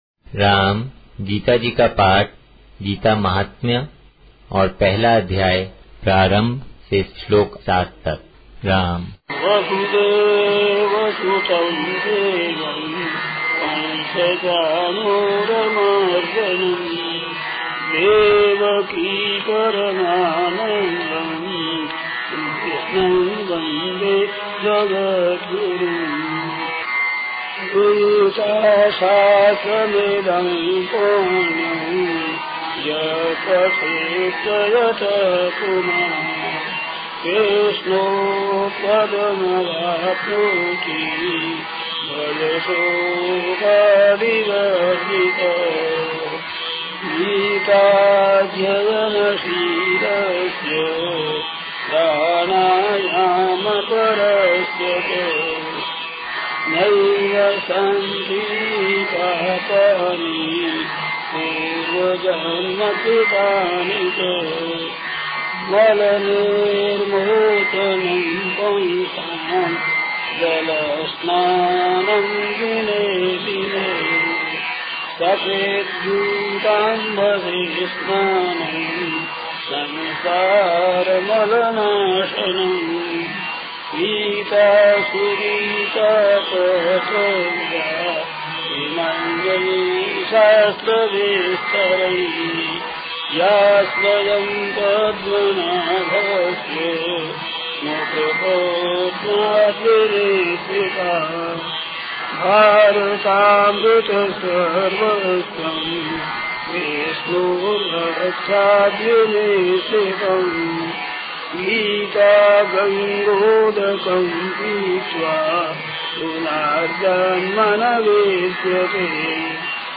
Welcome to Audio - Gita Paath in Sanskrit